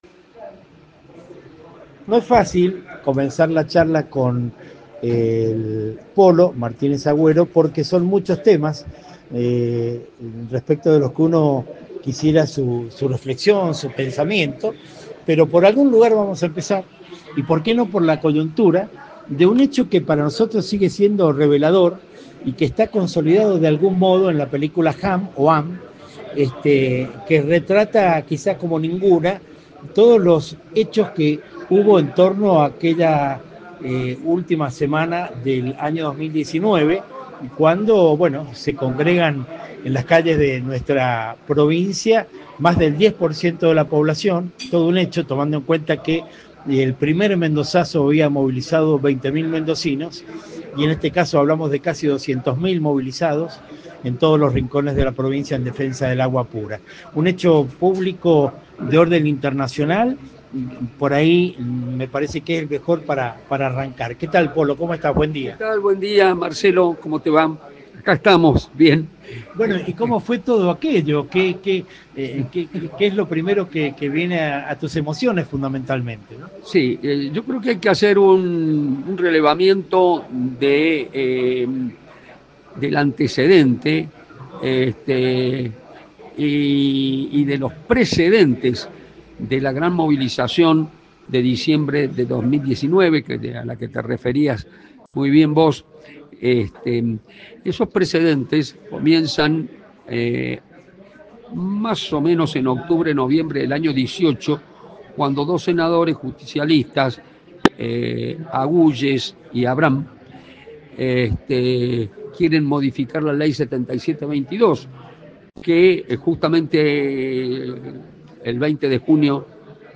Entrevistas militantes
Entrevista